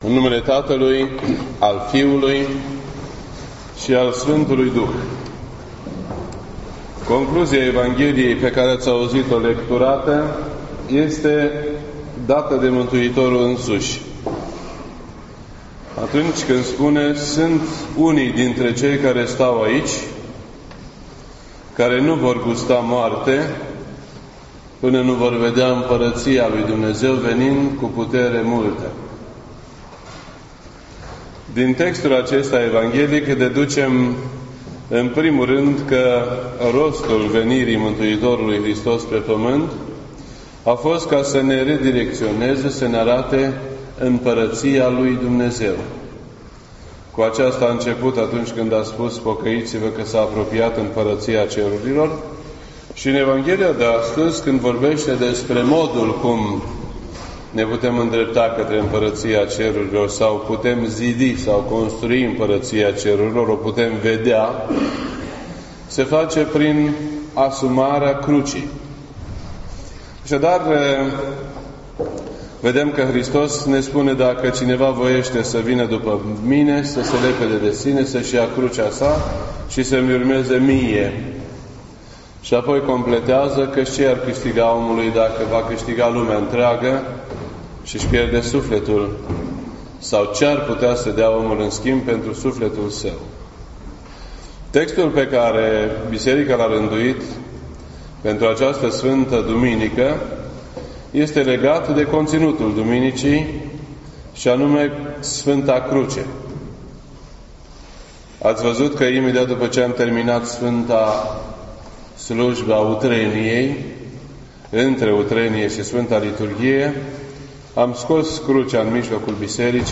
This entry was posted on Sunday, March 19th, 2017 at 6:56 PM and is filed under Predici ortodoxe in format audio.